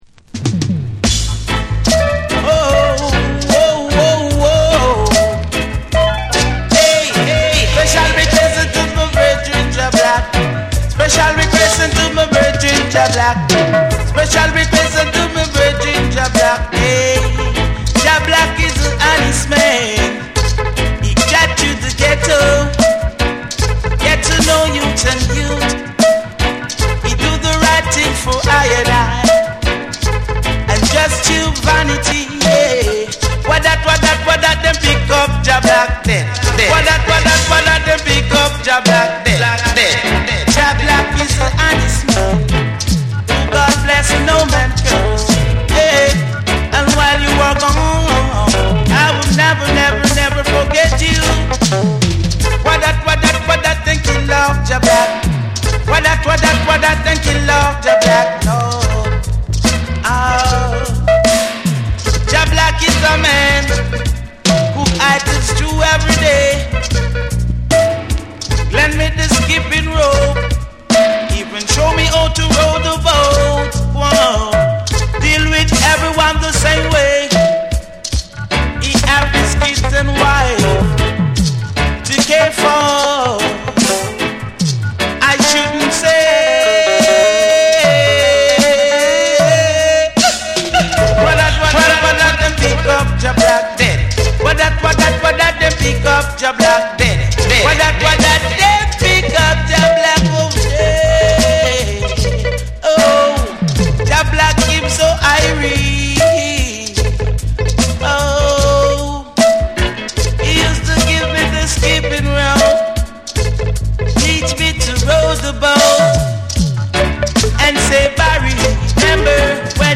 力強くリリカルな歌声が響く
ヘヴィーで土臭いグルーヴを堪能できる名盤シングル。